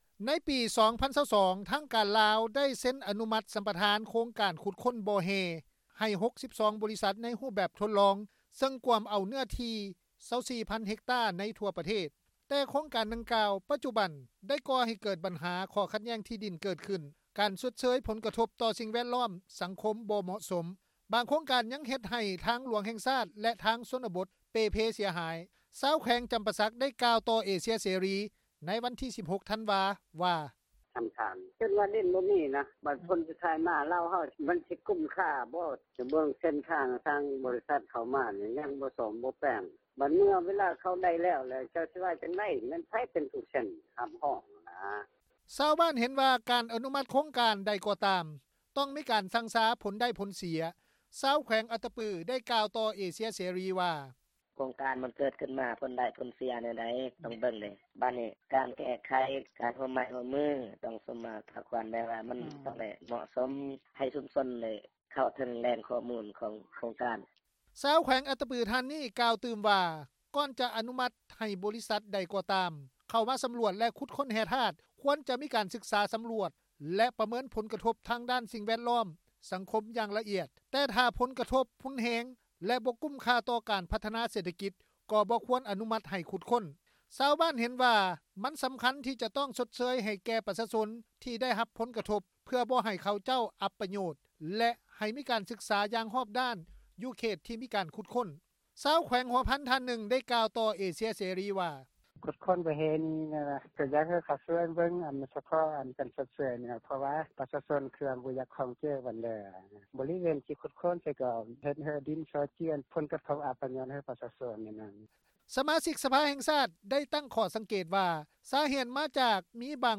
ຊາວແຂວງຈຳປາສັກ ໄດ້ກ່າວຕໍ່ວິທຍຸ ເອເຊັຽເສຣີ ໃນວັນທີ 16 ທັນວາ ວ່າ:
ຊາວແຂວງຫົວພັນ ທ່ານນຶ່ງ ໄດ້ກ່າວຕໍ່ວິທຍຸ ເອເຊັຽ ເສຣີ ວ່າ:
ທ່ານ ຈັດຕະວາ ແກ້ວຄຳເພັດ ສະມາຊິກສະພາແຫ່ງຊາຕ ເຂດເລືອກຕັ້ງທີ 7 ແຂວງໄຊຍະບູຣີ ໄດ້ກ່າວຢູ່ໃນກອງຊຸມສໄມ ສາມັນເທື່ອທີ 4 ຂອງສະພາແຫ່ງຊາຕ ຊຸດທີ 9 ຫຼື ກອງປະຊຸມສະພາແຫ່ງຊາຕ ໃນວັນທີ 8 ທັນວາ ທີ່ຜ່ານມາ ວ່າ: